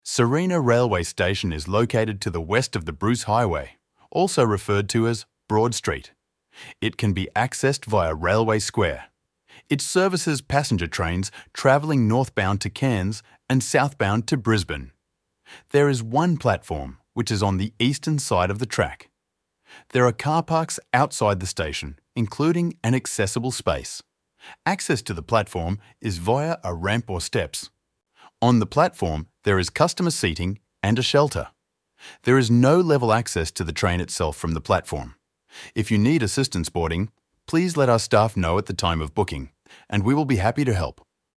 Audio description